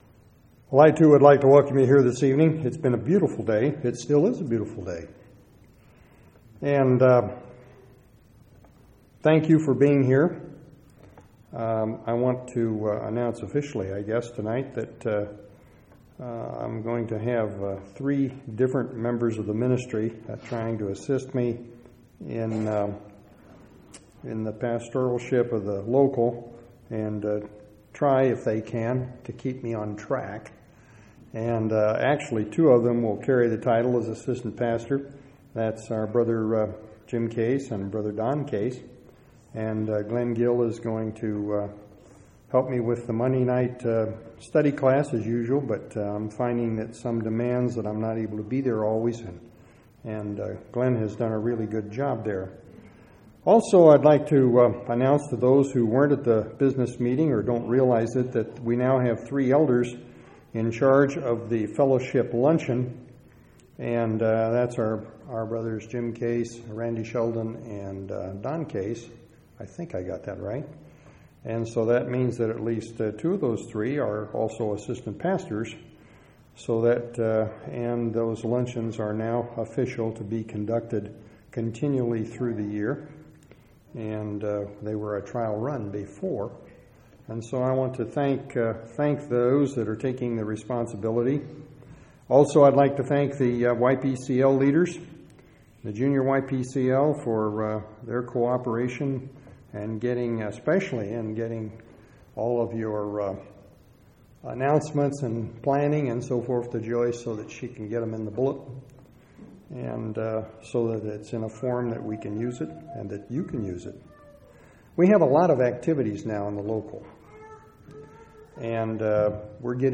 9/28/2003 Location: Temple Lot Local Event